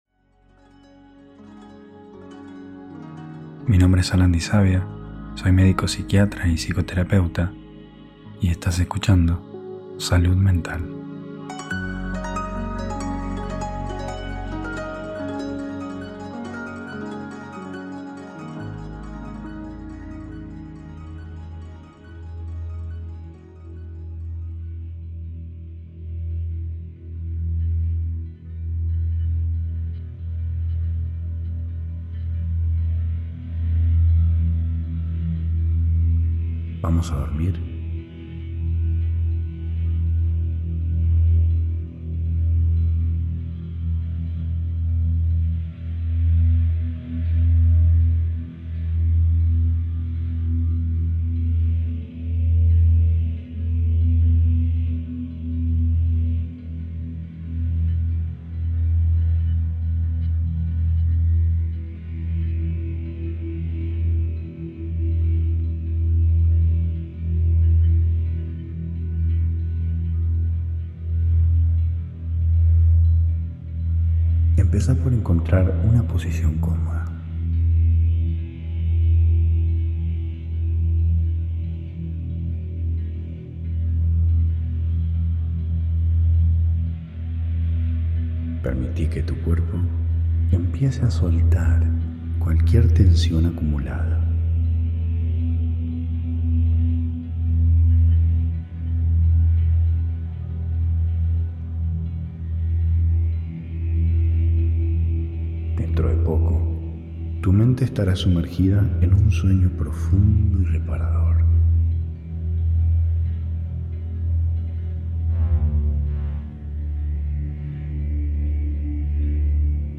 Hipnosis guiada para dormir.